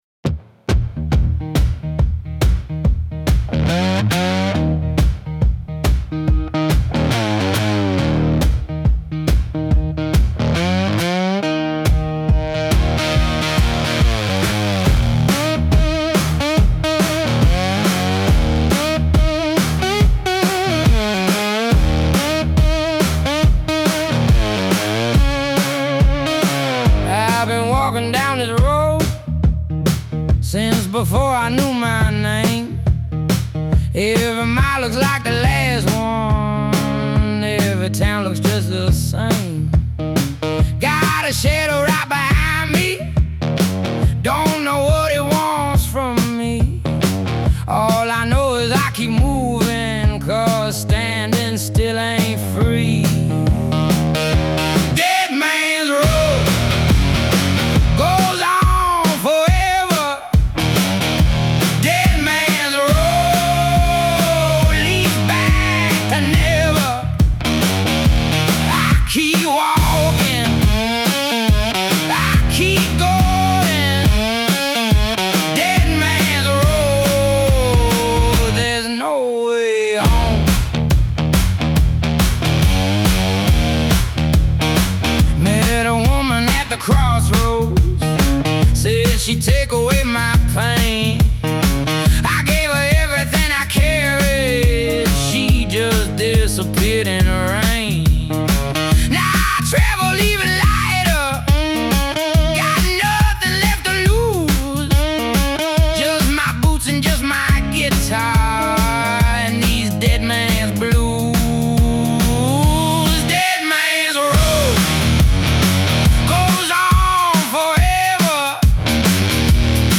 delta blues, garage rock, blues rock